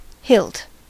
Ääntäminen
Synonyymit haft handle Ääntäminen US Tuntematon aksentti: IPA : /hɪlt/ Haettu sana löytyi näillä lähdekielillä: englanti Käännös Ääninäyte Substantiivit 1.